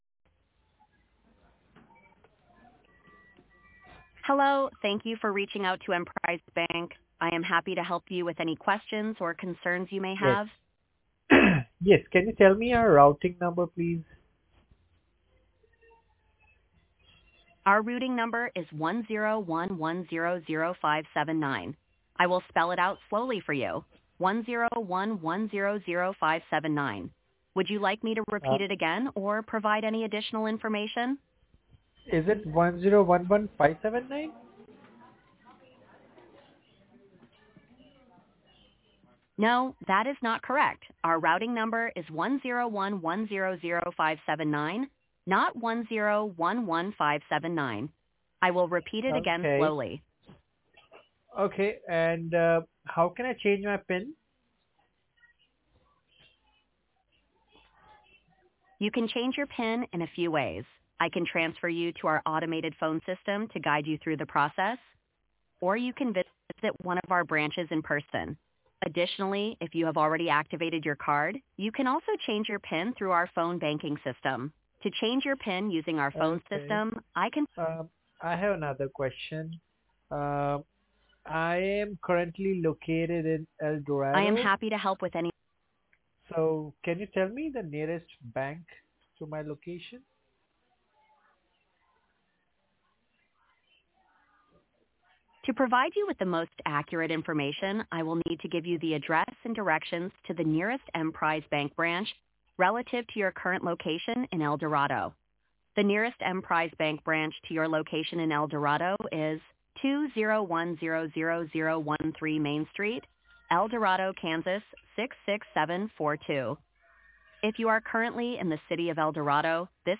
Live Voice Bot Demo - Latency, Accuracy, Intelligence
emprise-bank-AI-bank-demo.mp3